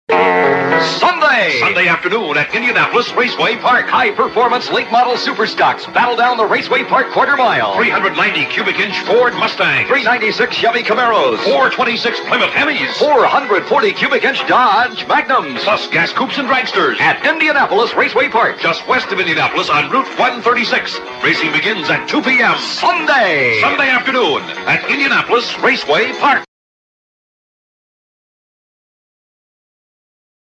Drag Strip Radio Spots